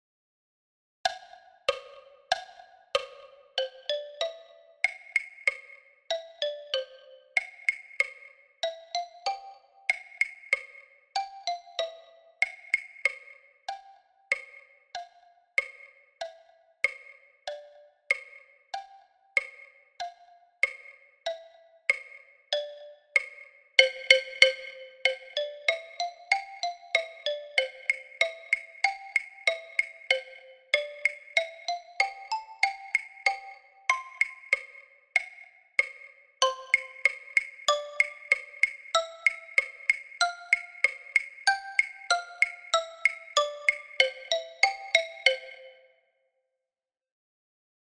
Les jumeaux – xylo et claves à 95 bpm